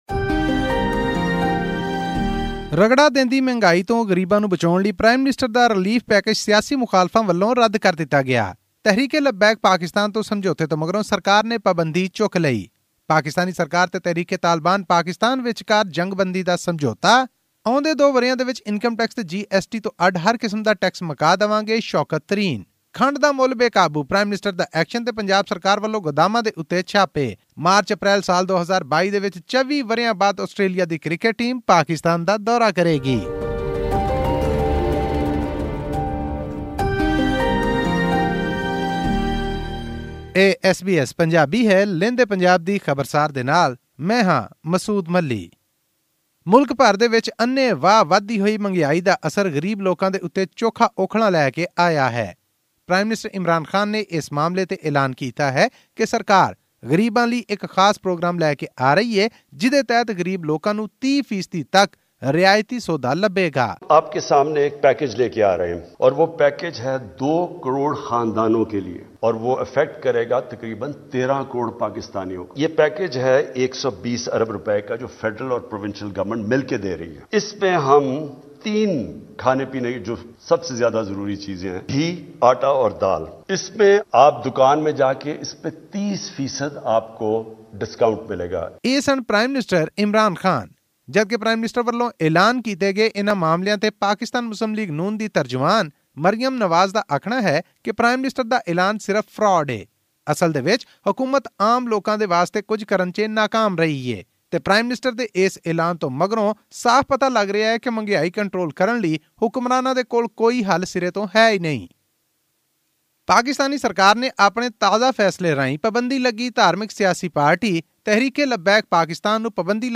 Opposition leaders have questioned Prime Minister Imran Khan's Rs 120 billion ($708 million) relief package aimed at mitigating the hardships of the inflation-hit public. This and more in our weekly news update from Pakistan.